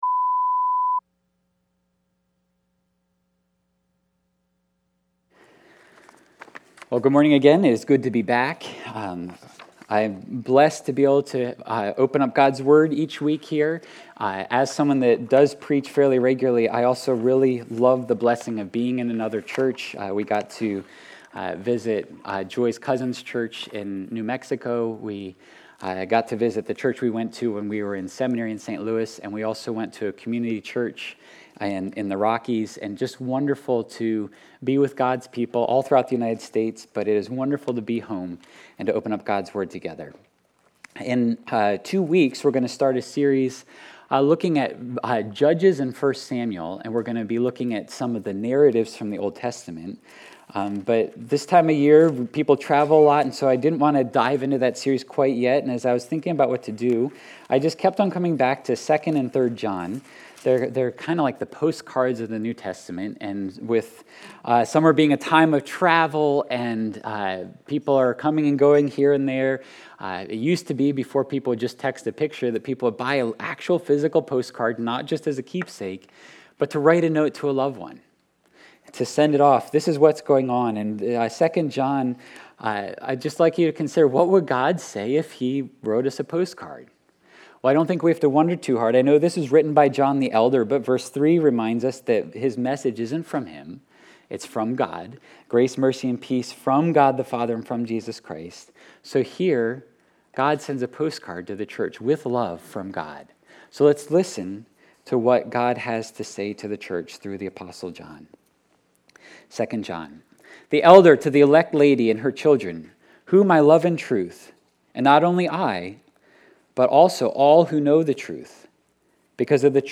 Sunday Worship – August 28 of 2022 – With Love, From God